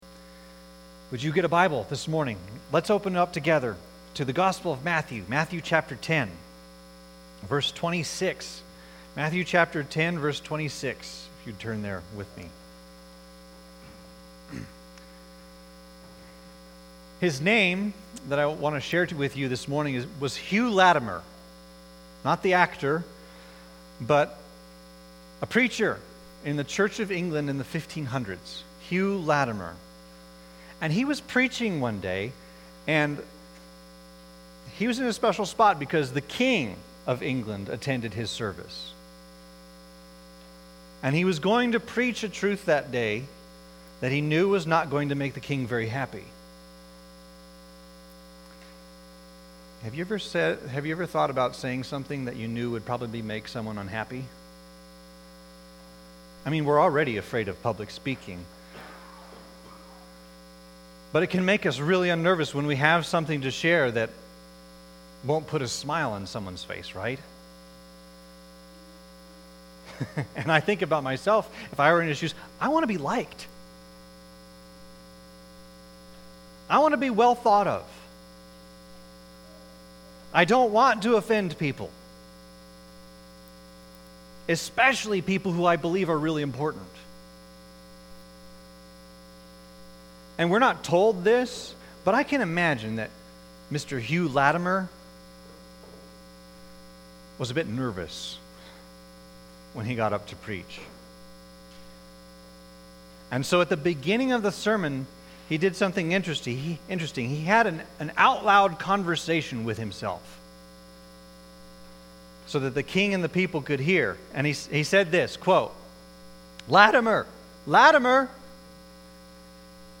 Sermons | York Evangelical Free Church